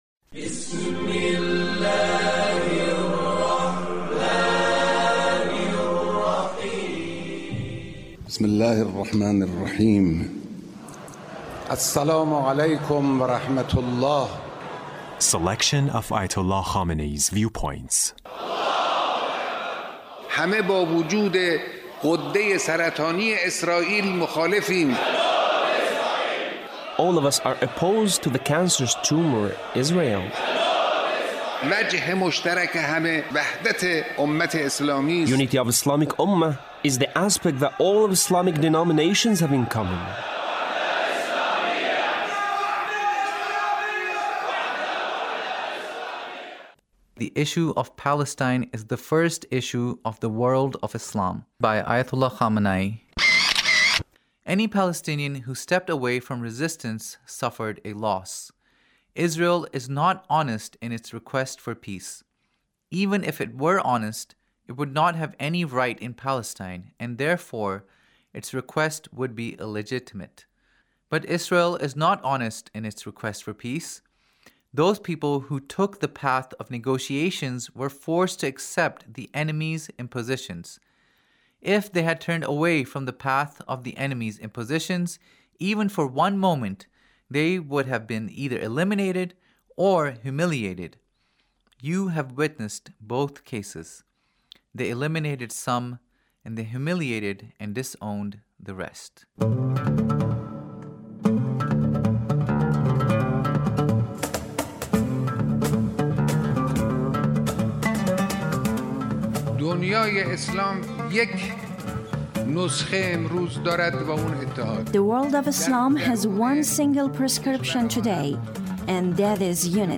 Leader's Speech (1873)